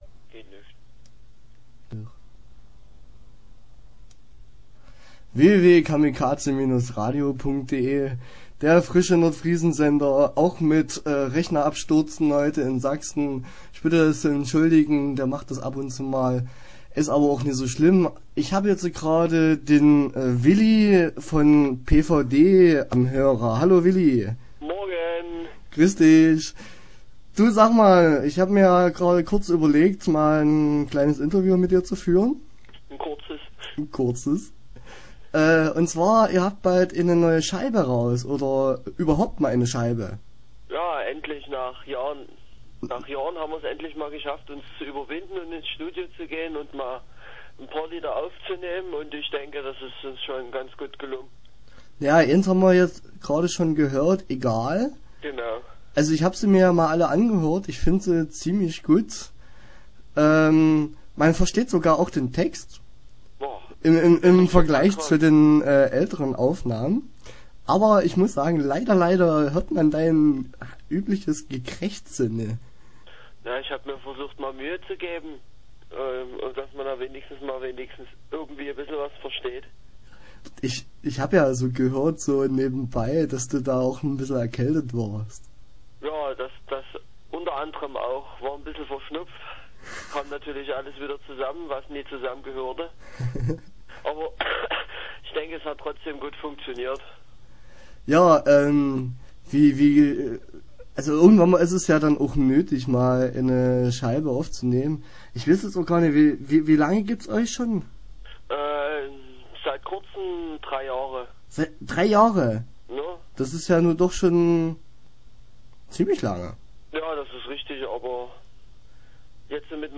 Start » Interviews » Platzverweis Deutschland